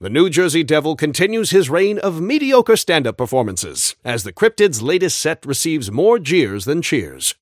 Newscaster_headline_76.mp3